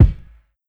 90's Kick.wav